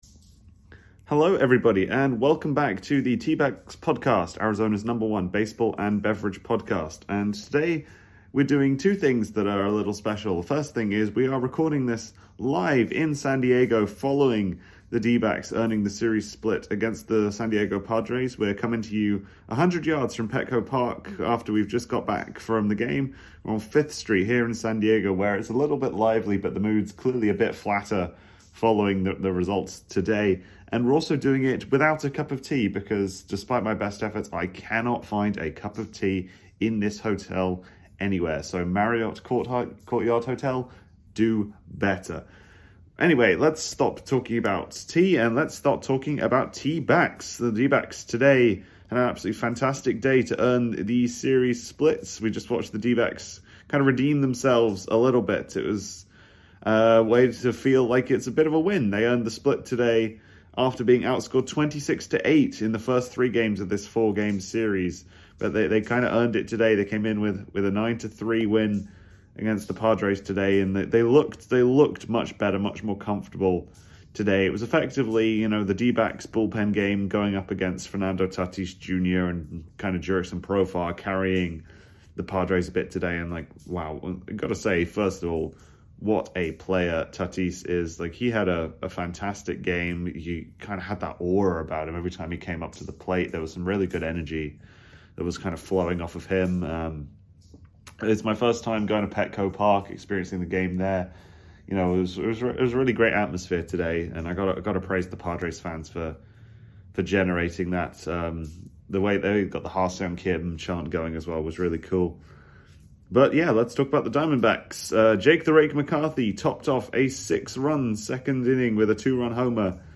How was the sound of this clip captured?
Live! from San Diego | Tea-Backs Podcast records a special on-site episode of Arizona's #1 Baseball and Bevarage podcast from Petco Park, home of the San Diego Padres